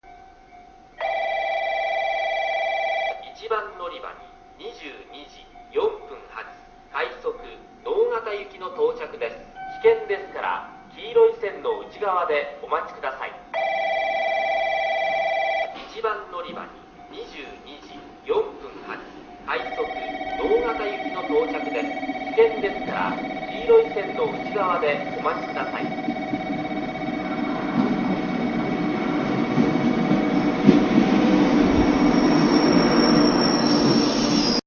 1番のりば接近放送(快速　直方行き）
スピーカーはFPS平面波ですが、香椎線ホームは遠隔放送があるのでそれ用のTOAラッパ型もあります。
※当駅の福北ゆたか線ホームは収録環境の都合で音質が悪いです。